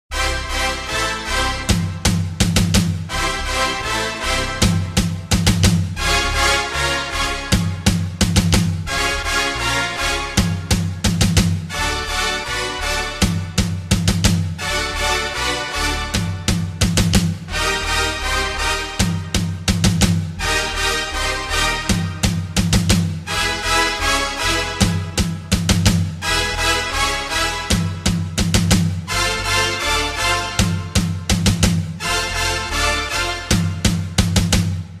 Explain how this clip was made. Tags: Princeton Hockey Baker Rink